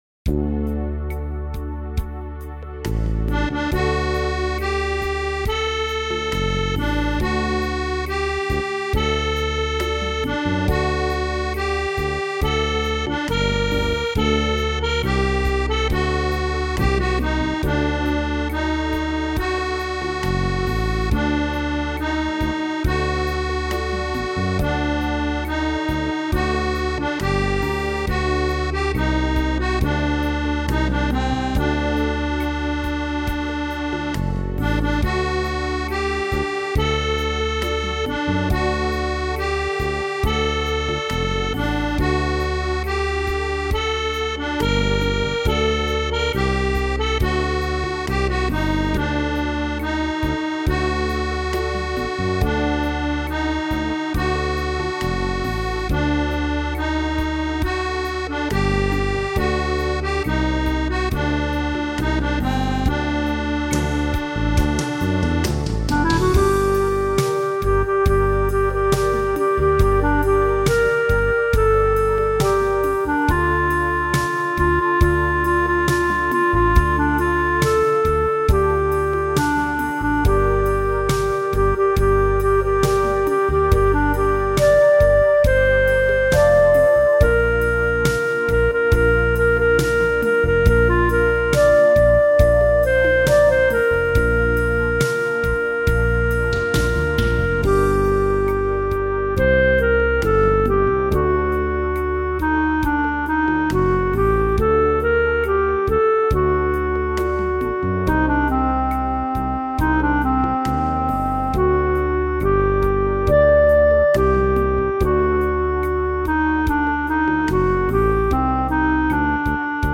ז'אנר: Nigunim.